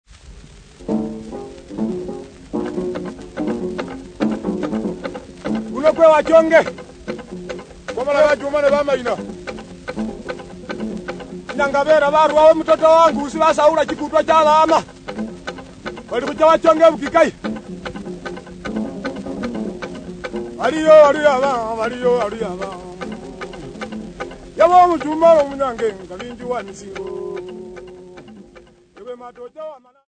Bukusu men
Popular music--Africa
field recordings
Fighting song accompanied by 2 Litungu 7 string lyres and 1 Luhingele long inverted bowl